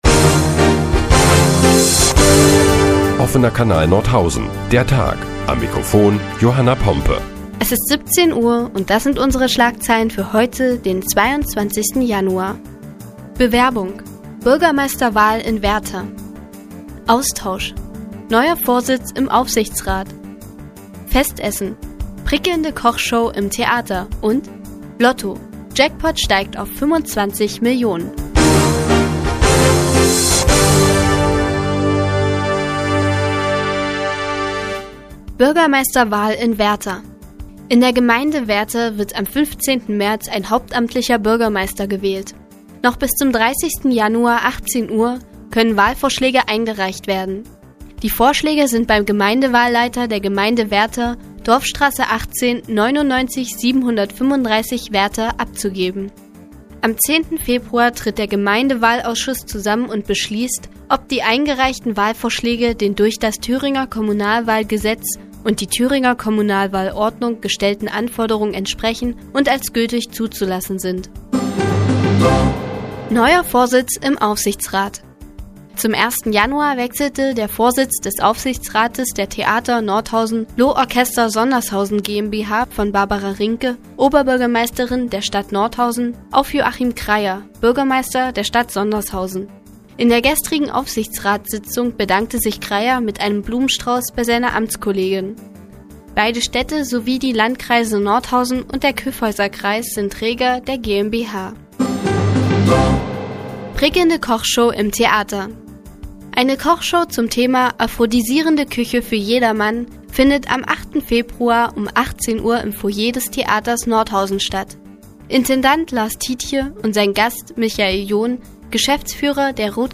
Die tägliche Nachrichtensendung des OKN ist nun auch in der nnz zu hören. Heute geht es unter anderem um die Bürgermeisterwahl in Werther und 25 Millionen Euro im Lotto-Jackpot.